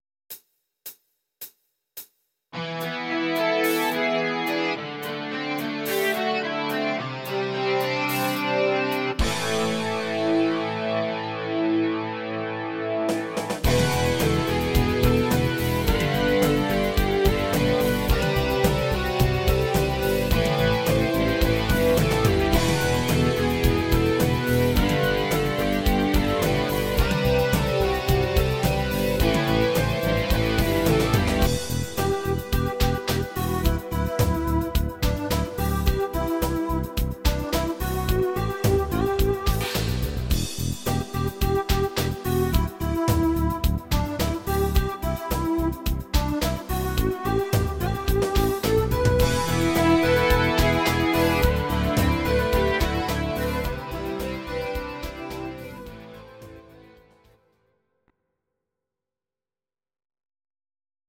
Audio Recordings based on Midi-files
Our Suggestions, Pop, German, Medleys